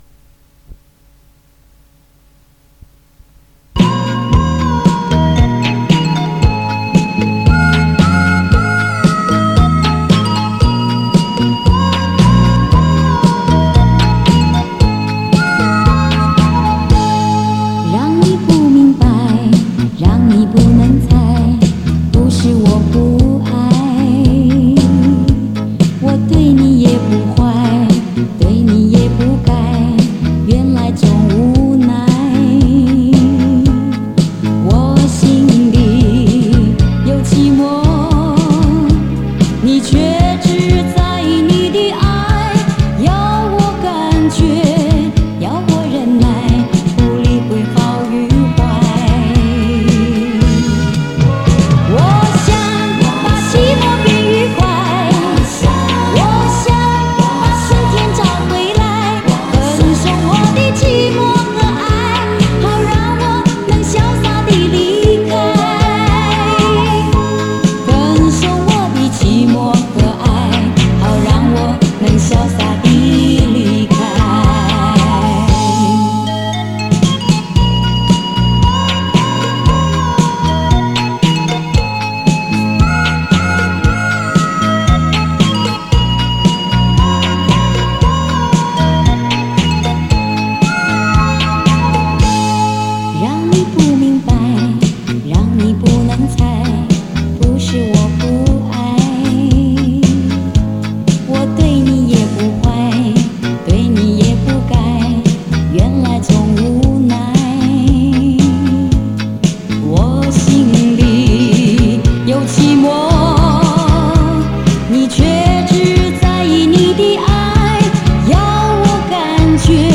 磁带数字化：2022-09-08